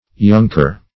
Younker \Youn"ker\, n. [D. jonker, jonkeer; jong young + heer a